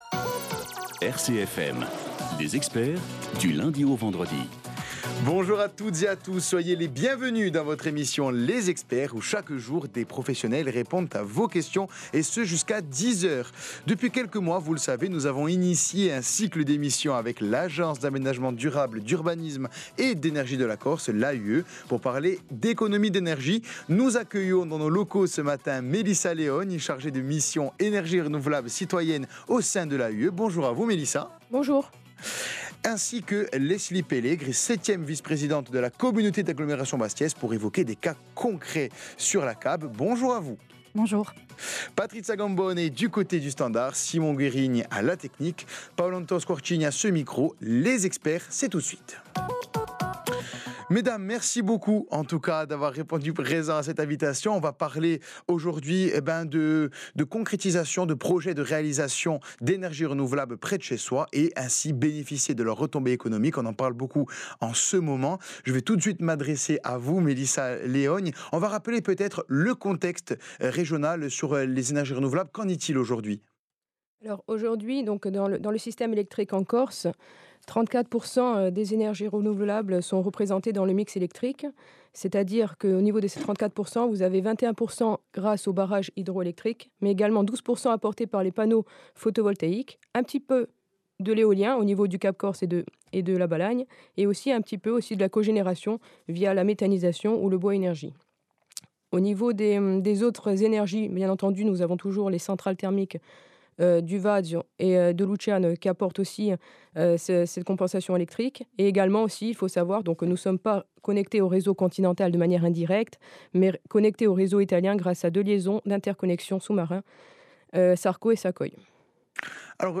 répond aux questions
des auditeurs dans l'émission Les Experts sur RCFM